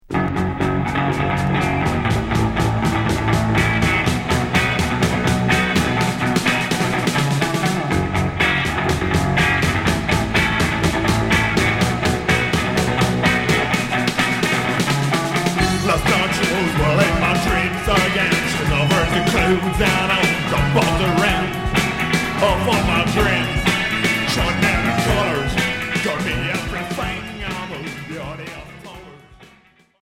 Psychobilly surf